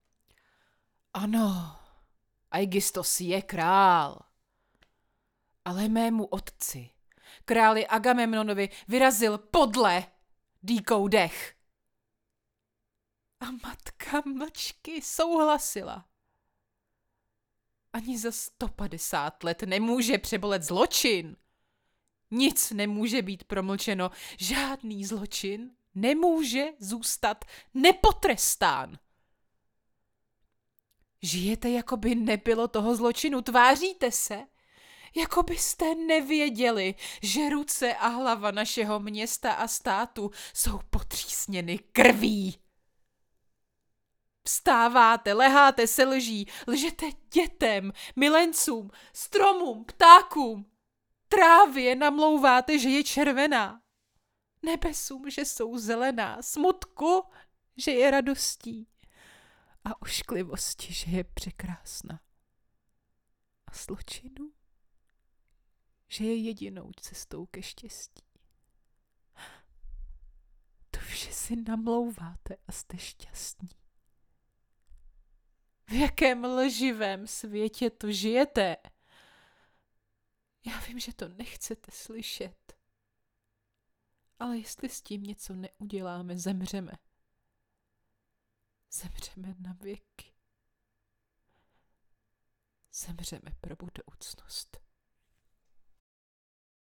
Umím: Voiceover
Ženský hlas - Voice over/ Dabing
monolog.mp3